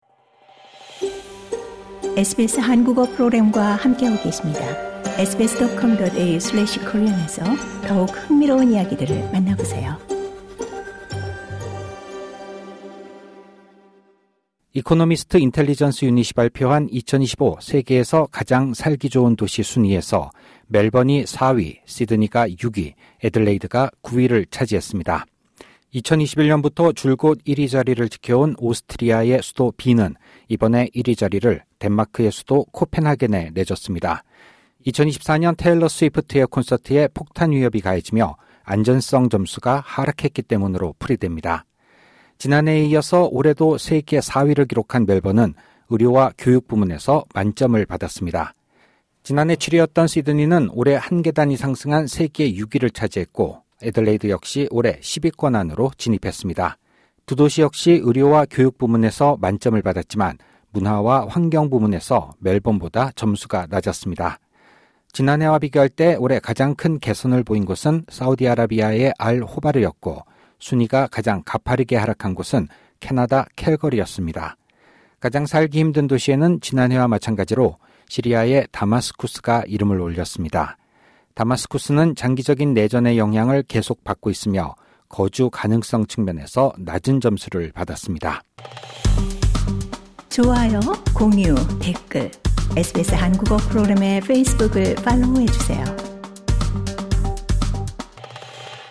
휴대전화 만지면 벌금 1,000달러 SBS Korean 04:41 Korean 상단의 오디오를 재생하시면 뉴스를 들으실 수 있습니다.